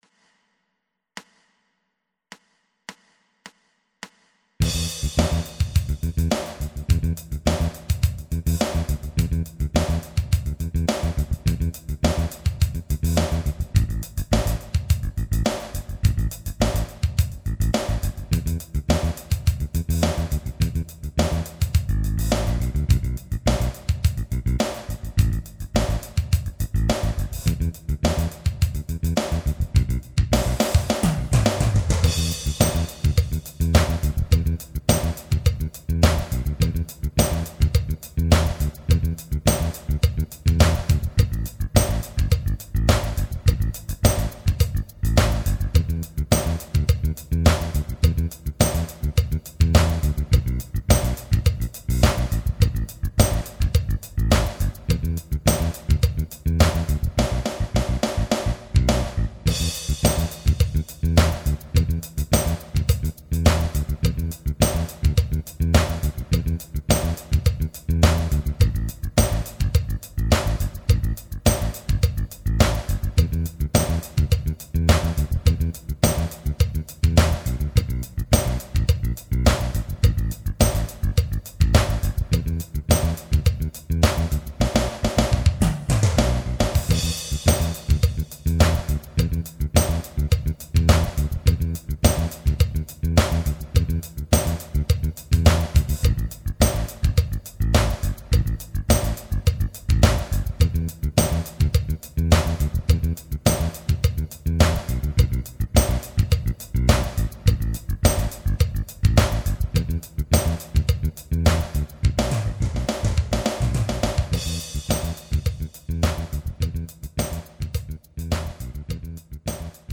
Funky Blues
base per sperimentare la ritmica proposta nel brano, o eventualmente delle ritmiche costruite da voi stessi.